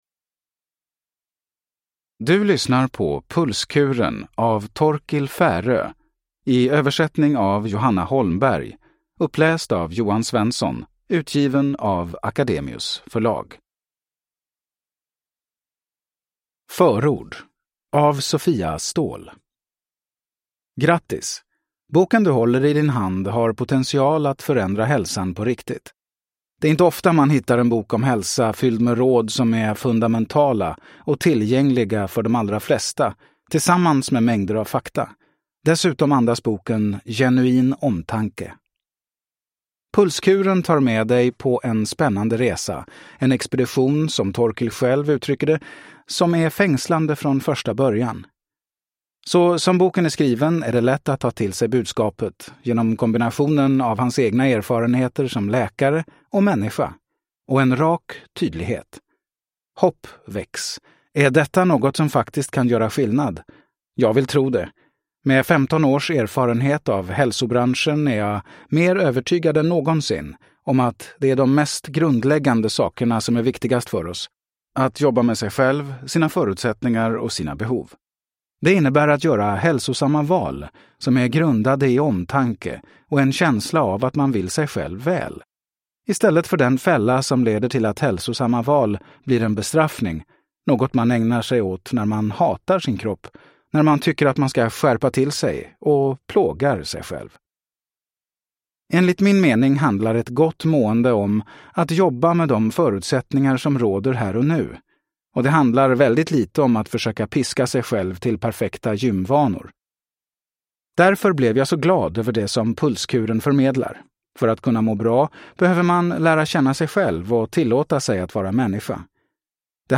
Pulskuren : Stressa rätt, sov bättre, prestera mer och lev längre – Ljudbok